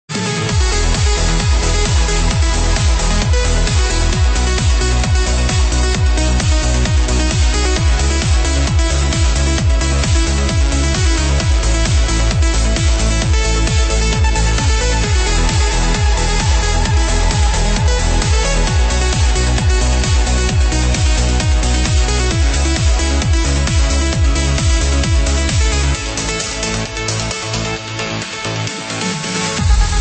[Lo-Fi preview]